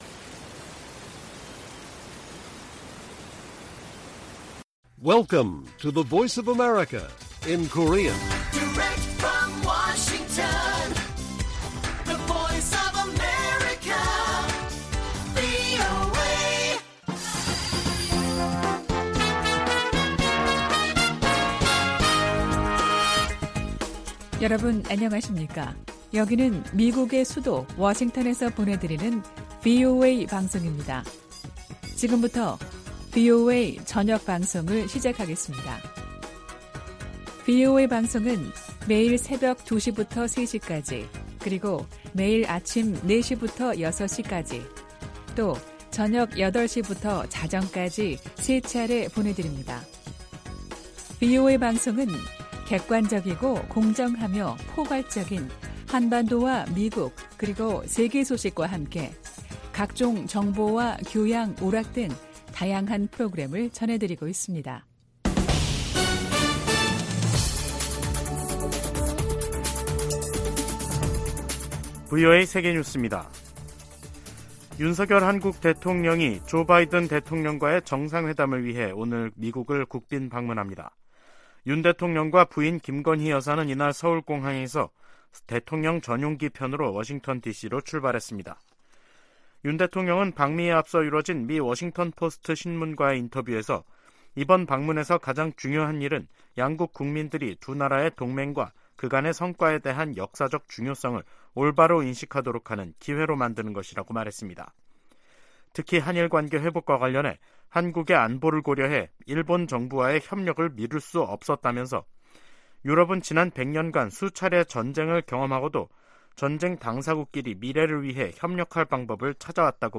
VOA 한국어 간판 뉴스 프로그램 '뉴스 투데이', 2023년 4월 24일 1부 방송입니다. 백악관은 윤석열 한국 대통령의 국빈 방문이 미한 관계의 중요성을 증명하는 것이라고 강조했습니다. 윤 대통령은 방미를 앞두고 워싱턴포스트 인터뷰에서 미한동맹의 중요성을 거듭 강조했습니다. 미 국무부가 핵보유국 지위와 관련한 북한의 주장에 대해 불안정을 조성하는 행동을 자제하고 협상에 복귀할 것을 촉구했습니다.